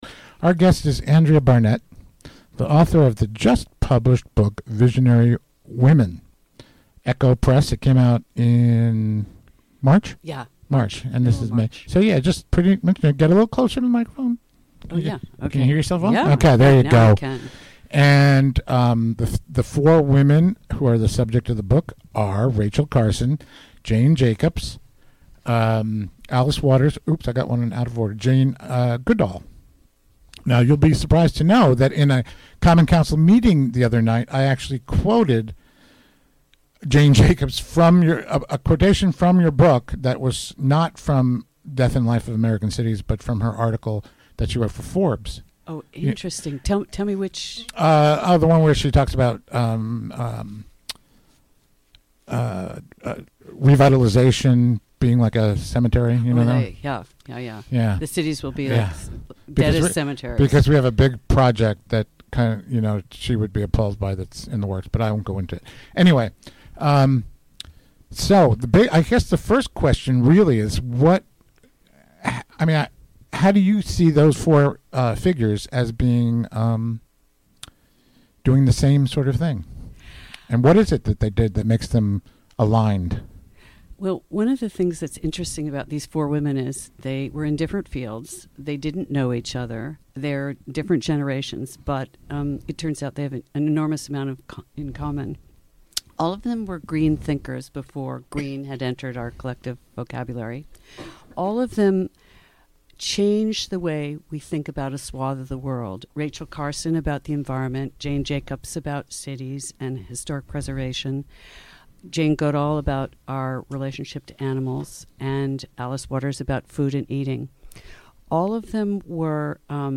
Recorded live during the WGXC Afternoon Show Thursday, May 10, 2018.